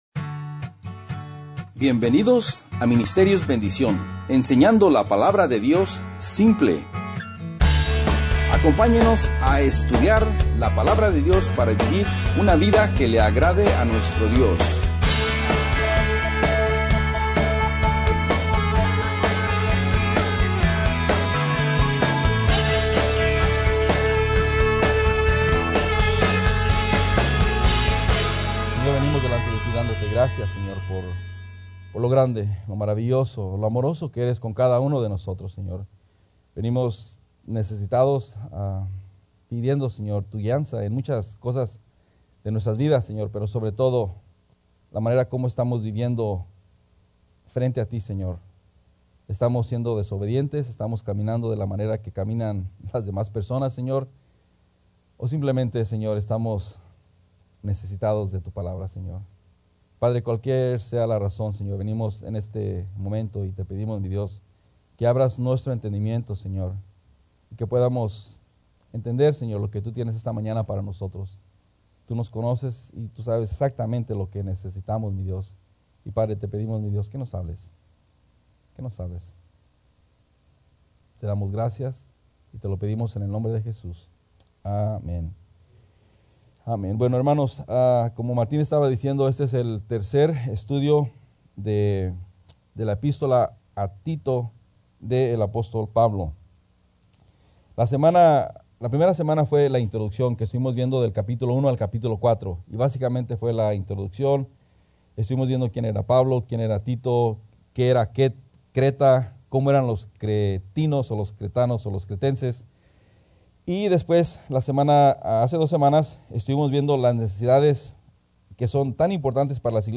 Titulo del Sermon